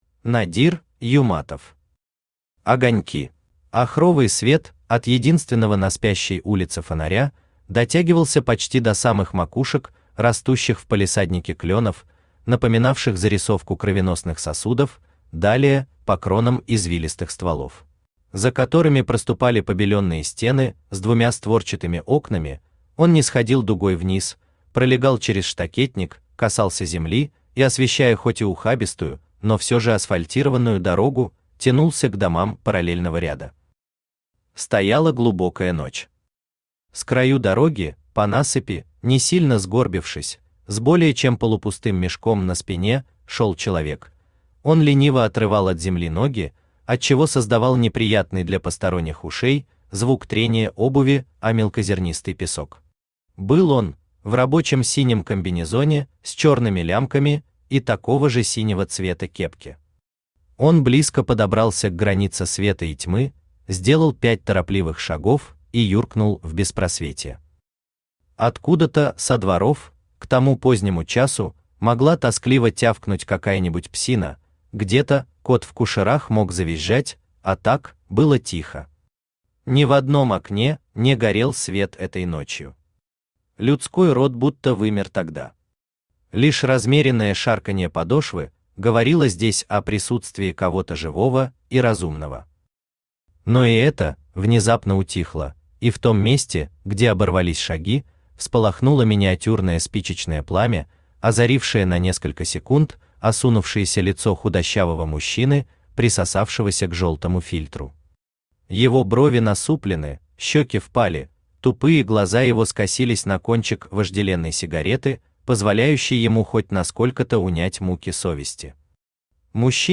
Aудиокнига Огоньки Автор Надир Юматов Читает аудиокнигу Авточтец ЛитРес.